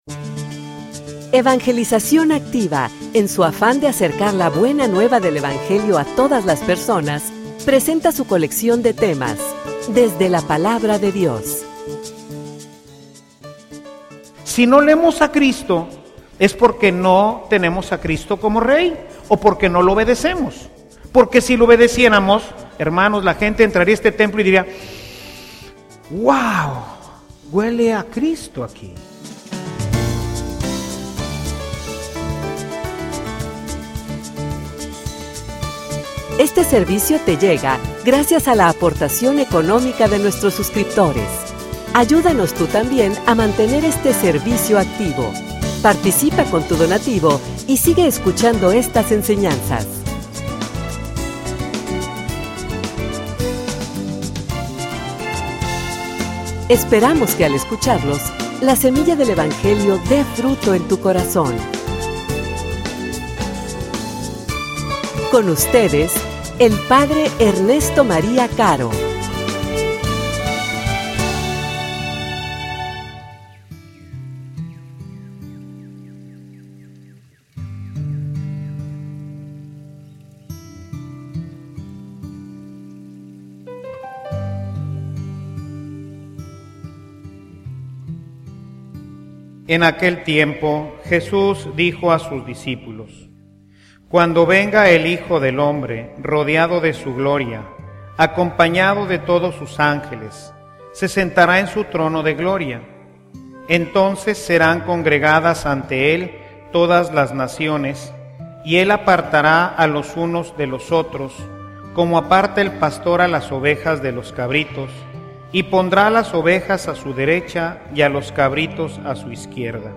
homilia_El_buen_olor_del_Evangelio.mp3